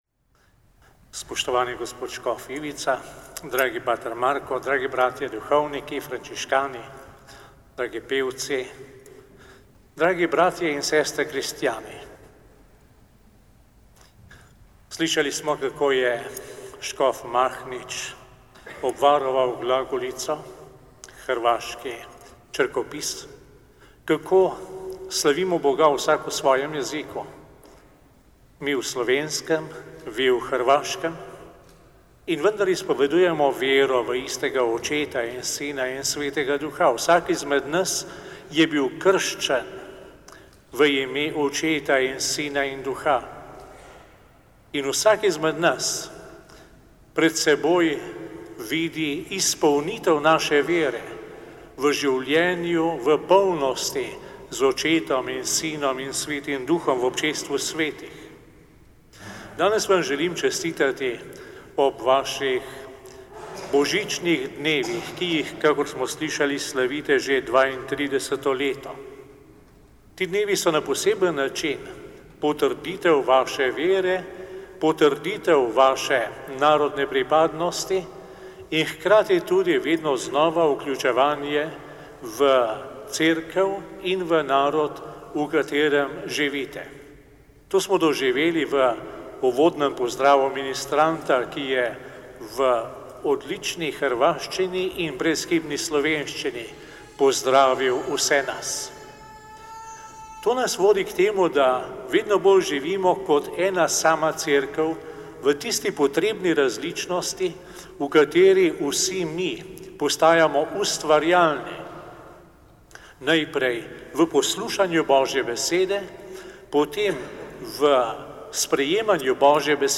Na blagdan Krštenja Gospodinova, u nedjelju 12. siječnja, Hrvatska katolička misija u Ljubljani po 32. put proslavila je svoje Božićne dane.
Poslušajte uvodni pozdrav mons. Stanislava Zorea, ljubljanskog nadbiskupa:
Uvodni-pozdrav-nadbiskupa-Stanislava-Zorea.mp3